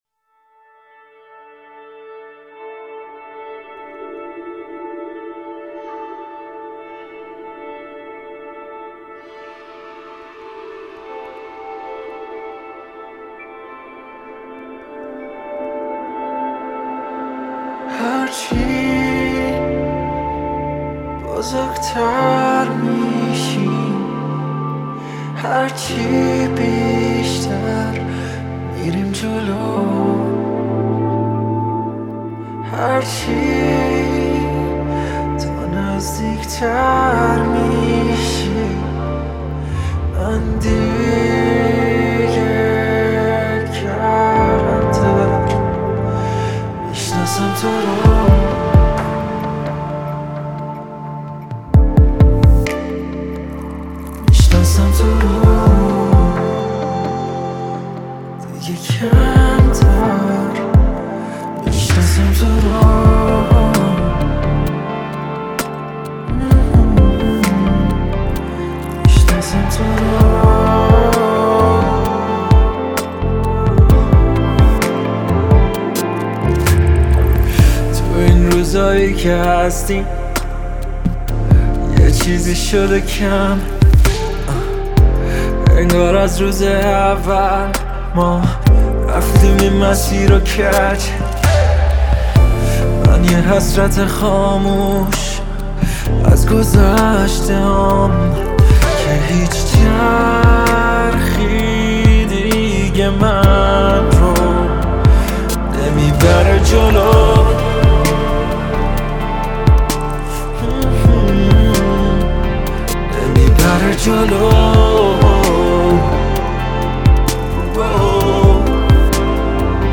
او این روزها بیشتر در سبک پاپ – راک می‌خواند.